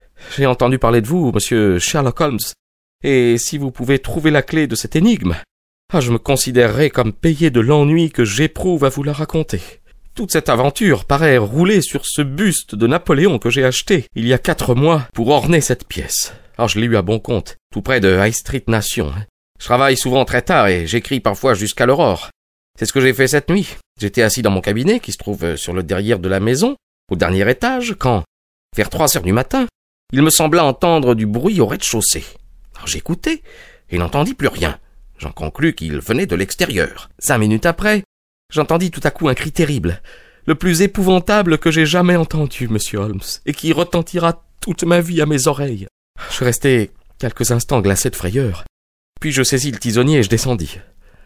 Diffusion distribution ebook et livre audio - Catalogue livres numériques
Cette adaptation audio est faite à la manière des grands raconteurs d'histoires, qui font revivre tous les personnages en les interprétant brillamment.